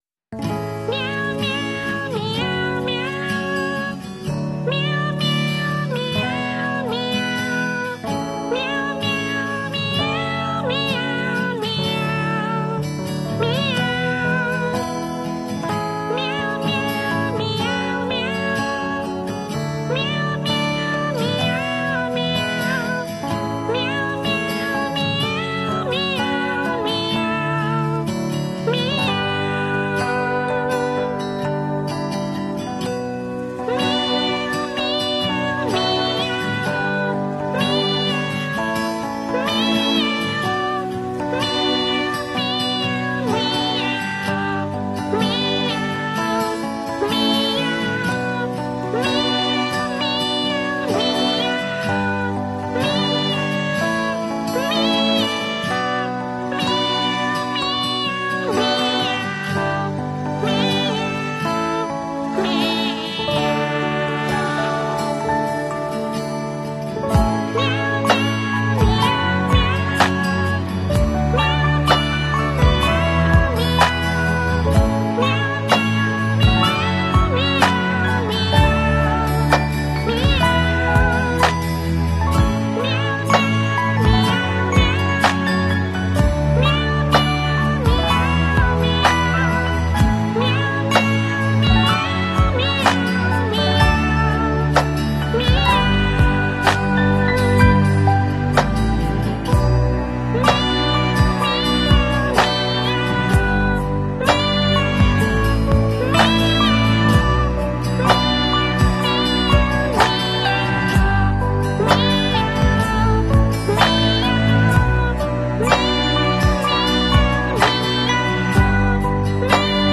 You Just Search Sound Effects And Download. tiktok sound effects meme Download Sound Effect Home